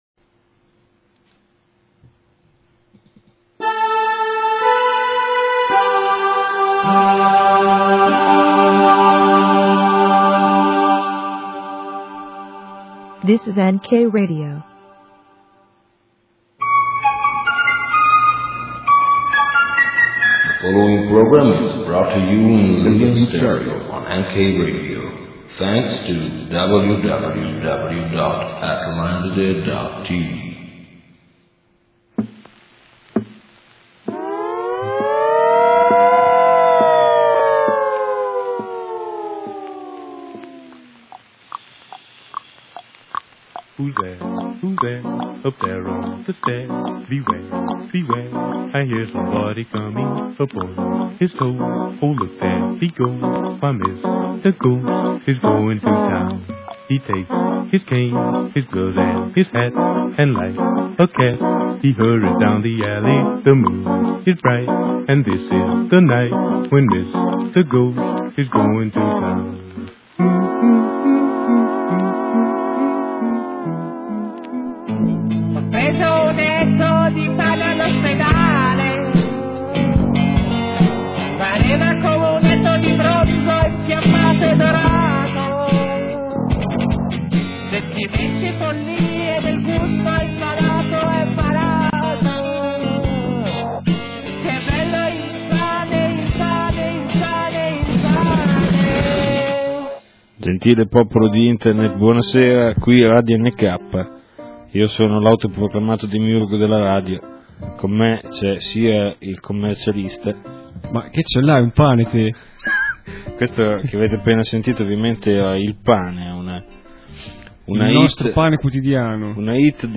Notate che in alcuni punti della registrazione si sentono i bbotti prodotti da questa massa di bambini (aargh, bambini!).